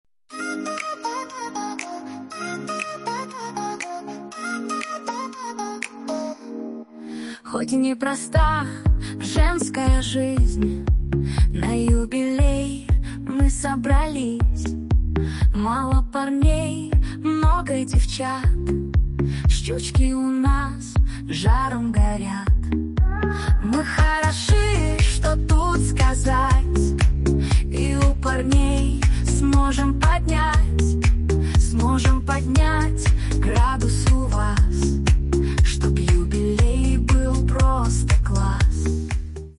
Фрагмент варианта исполнения: